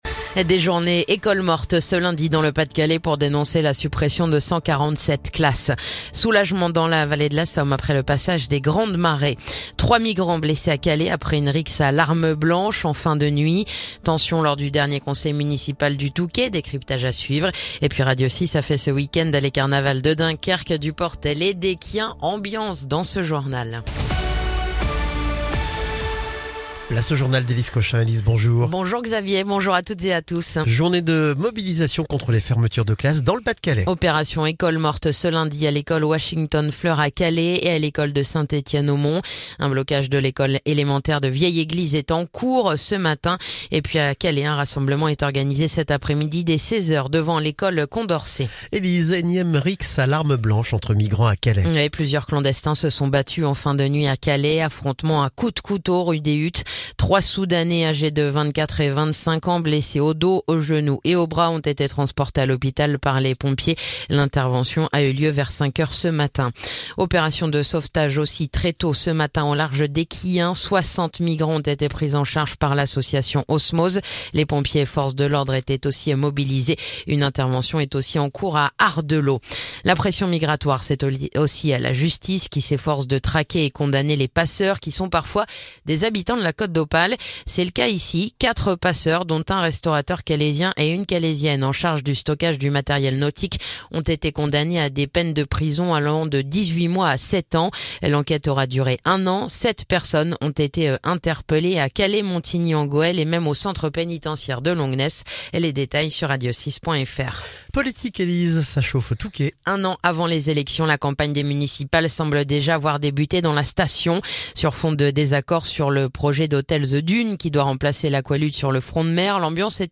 Le journal du lundi 3 mars